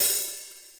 drum-hitfinish.wav